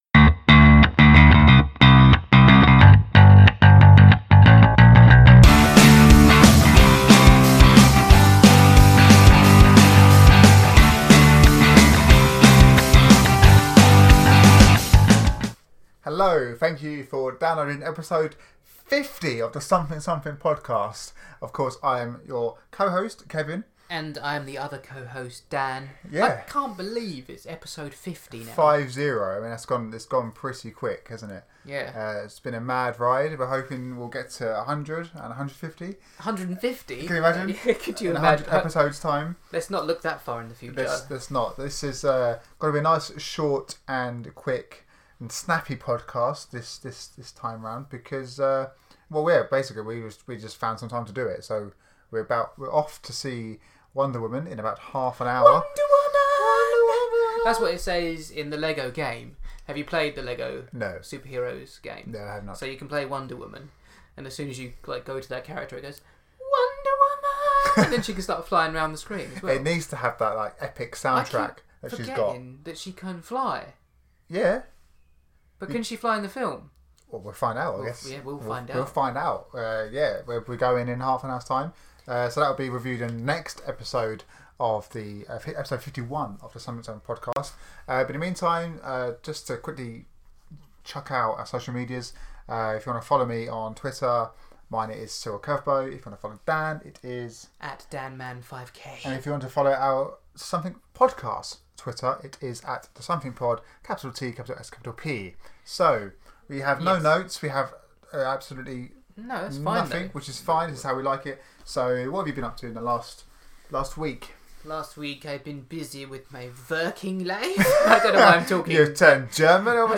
There is of course the usual banter between the two and laughs along the way!